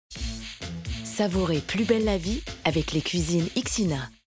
Billboard DM - Voix confiante